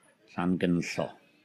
Welsh pronunciation) is a village and community in central Powys (formerly in Radnorshire), Wales, located about 5 miles west of Knighton.